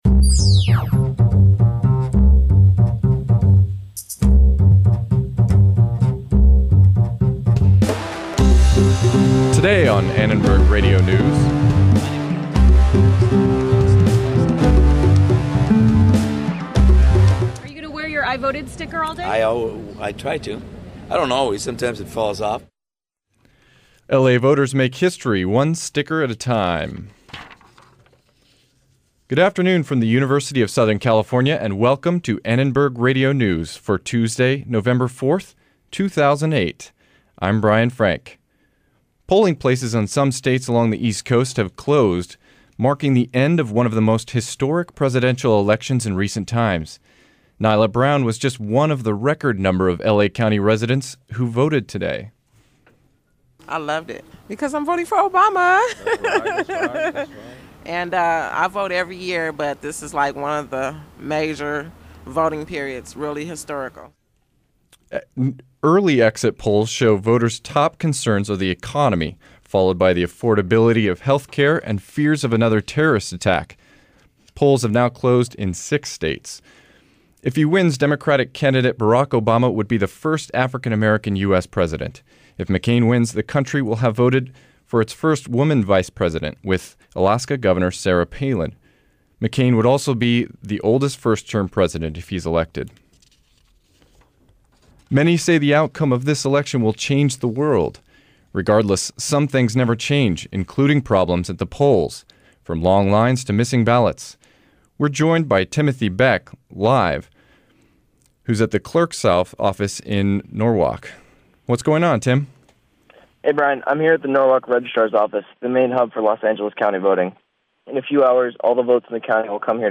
We hit the polls to get the latest on today's historic election, plus live coverage from the county clerk's office in Norwalk. We also hear from locals about what they think about the election, with a special feature about the problems facing first-time voters.